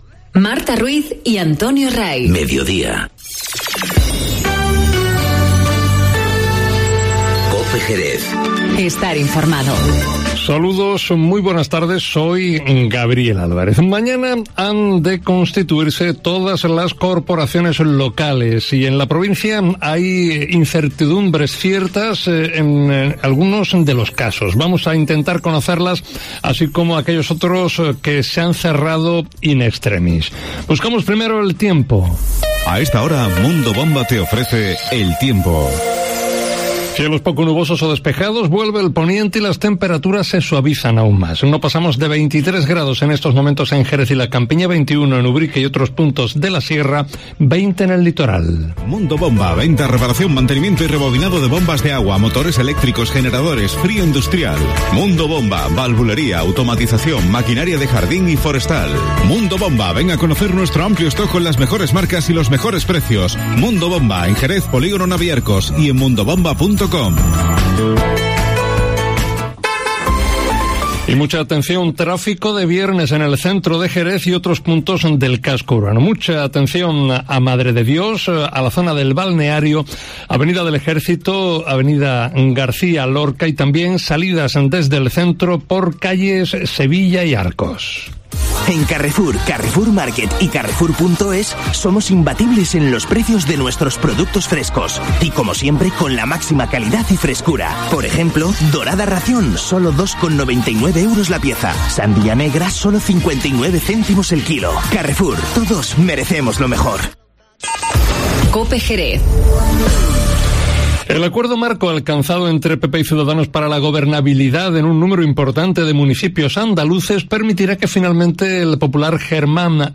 Informativo Mediodía COPE en Jerez 14-06-19